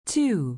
Index of /phonetones/unzipped/LG/KE990-Viewty/Keytone sounds/Bee-beep